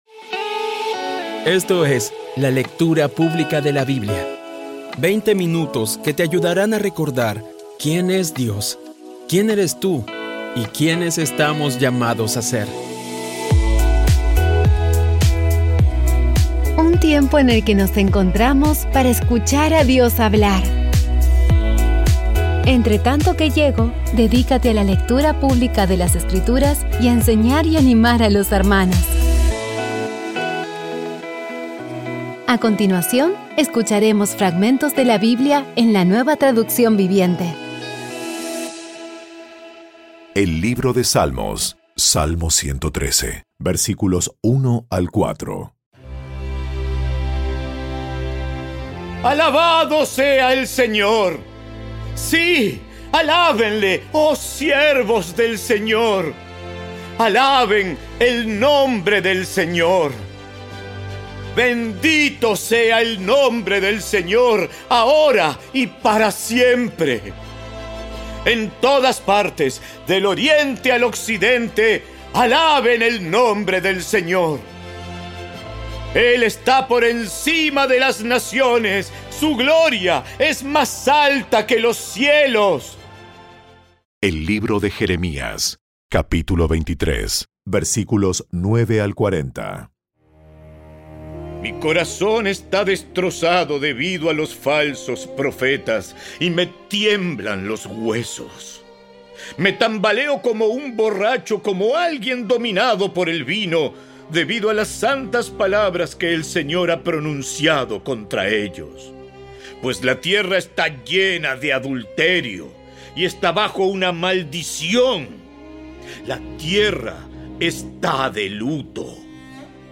Audio Biblia Dramatizada Episodio 282
Poco a poco y con las maravillosas voces actuadas de los protagonistas vas degustando las palabras de esa guía que Dios nos dio.